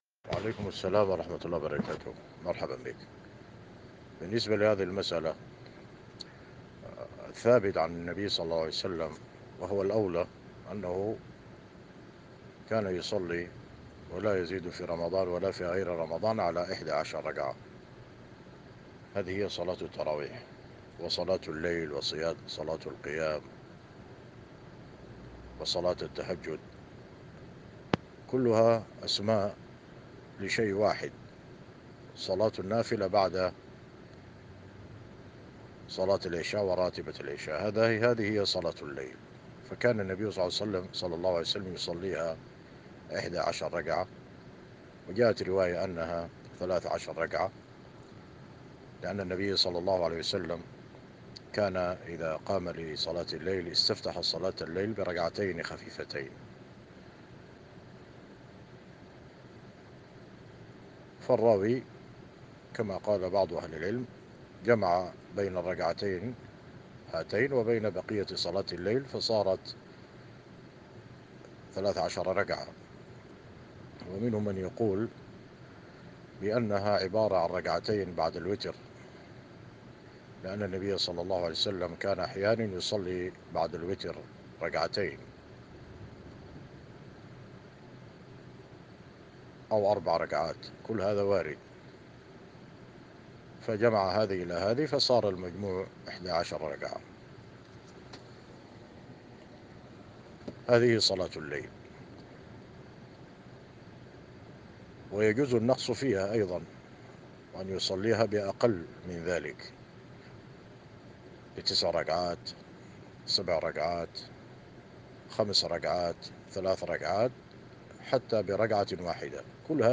[جواب صوتي]